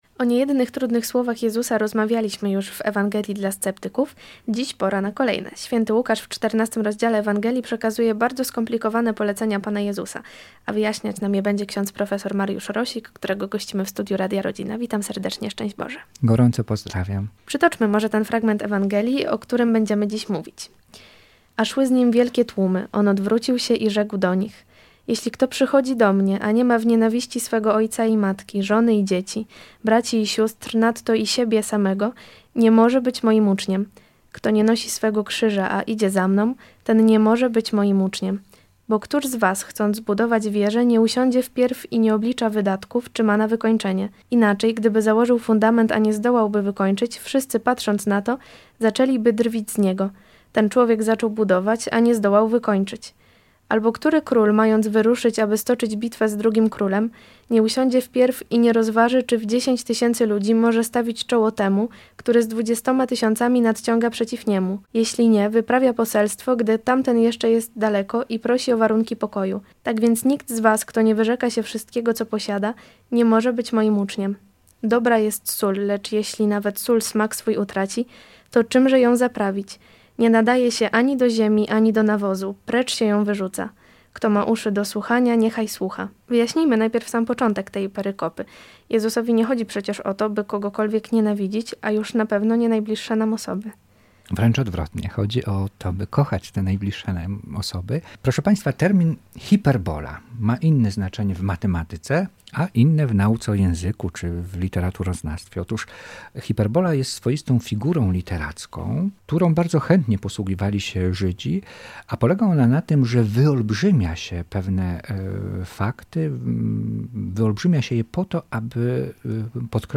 Audycję prowadzi zawsze wnikliwa w zadawaniu pytań